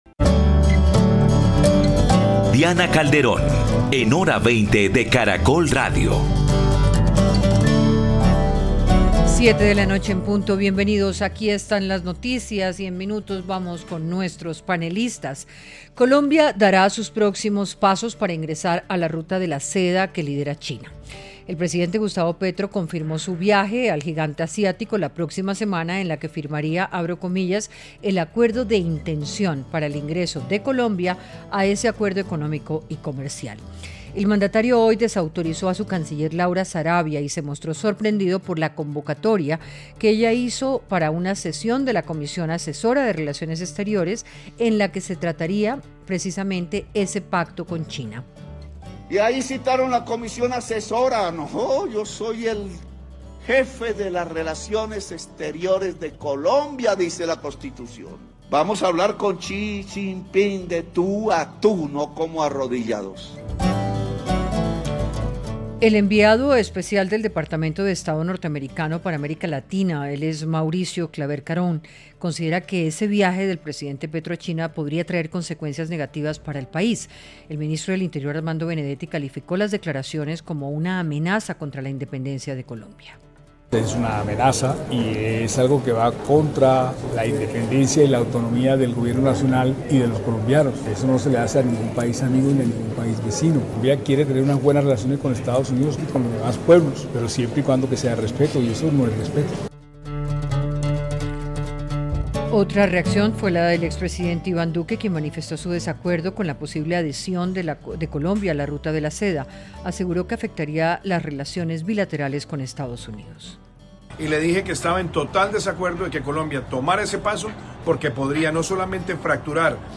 Panelistas analizaron las decisiones que toma el gobierno colombiano y el rumbo que se toma en materia diplomática ante el discurso del Presidente y la falta de entendimiento entre la Cancillería y la Casa de Nariño.